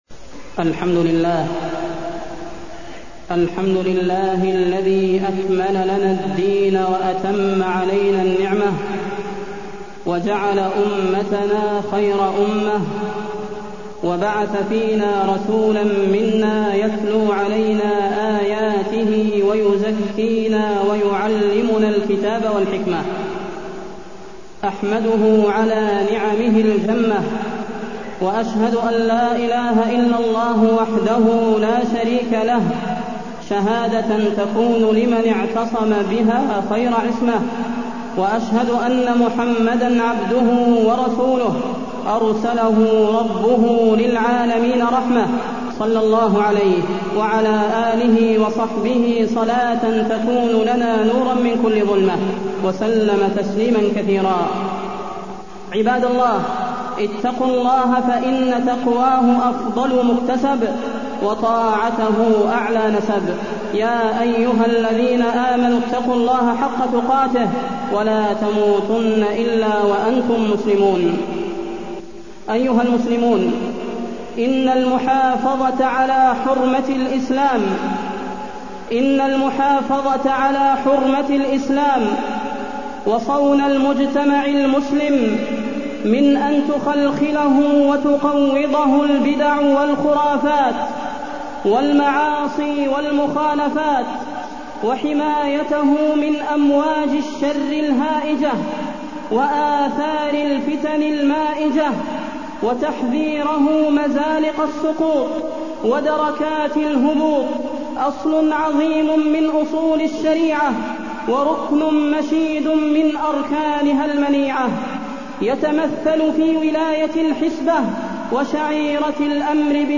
تاريخ النشر ١ ذو القعدة ١٤٢١ هـ المكان: المسجد النبوي الشيخ: فضيلة الشيخ د. صلاح بن محمد البدير فضيلة الشيخ د. صلاح بن محمد البدير الأمر بالمعروف والنهي عن المنكر